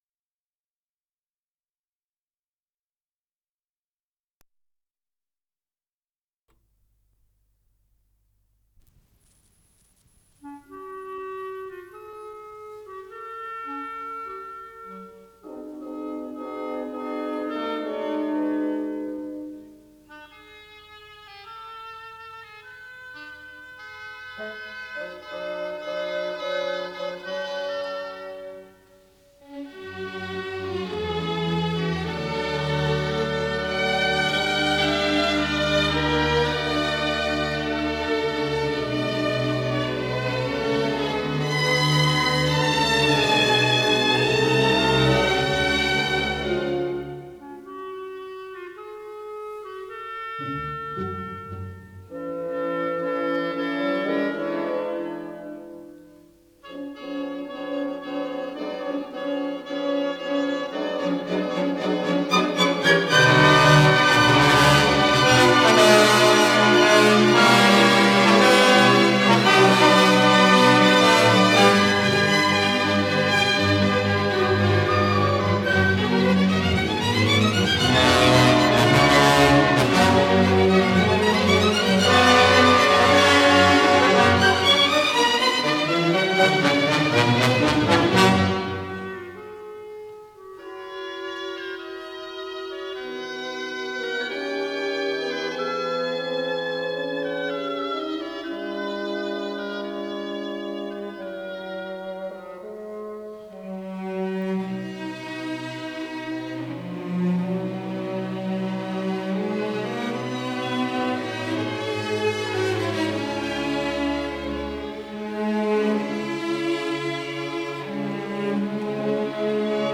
Исполнитель: Виктор Пикайзер - скрипка
Концерт №1 для скрипки с оркестром
Фа диез минор